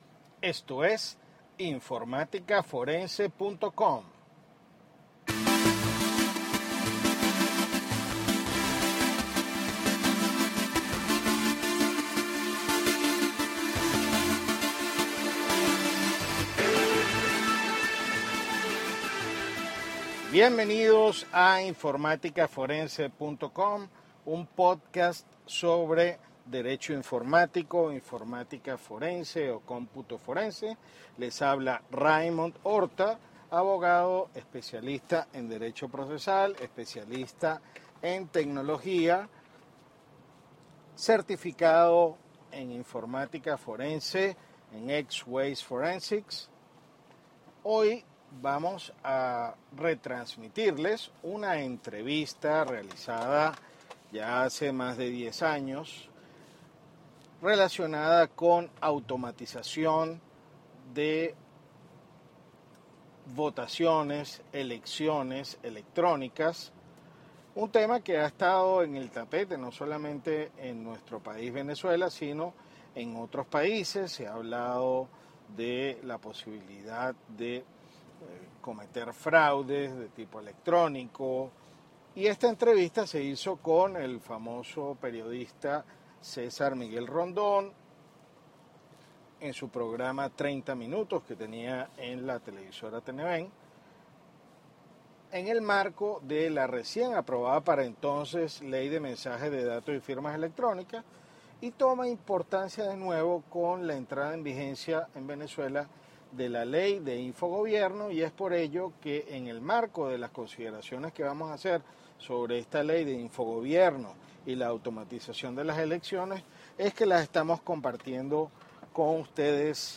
Entrevista
sobre el marco legal de la automatización de las elecciones y referendums en Venezuela en el programa 30 Minutos de Televen año 2004